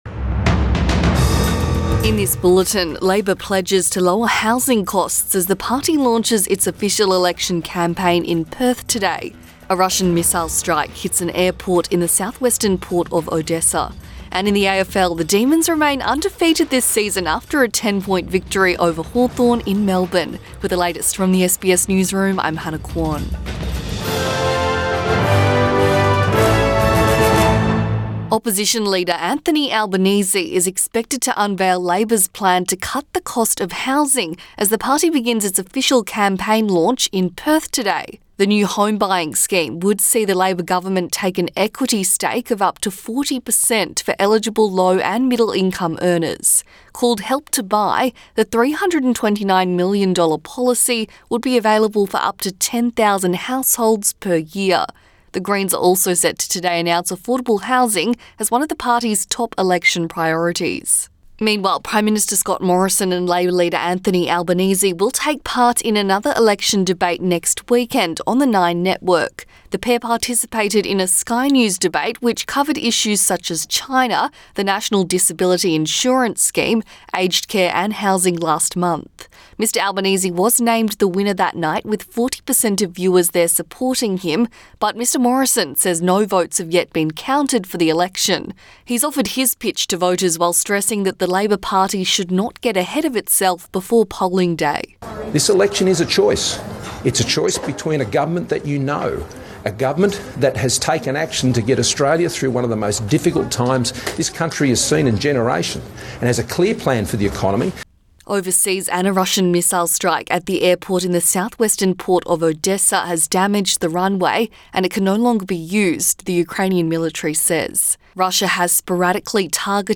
AM bulletin 1 May 2022